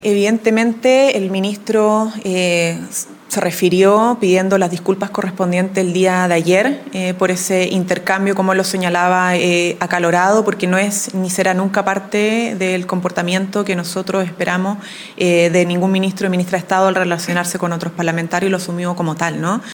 “El ministro se refirió pidiendo las disculpas correspondientes el día de ayer por ese intercambio acalorado, porque no es, ni será nunca, parte del comportamiento que nosotros esperamos de ningún ministro de Estado al relacionarse con otros parlamentarios”, sentenció la ministra Camila Vallejo.